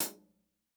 Closed Hats
TC Live HiHat 01.wav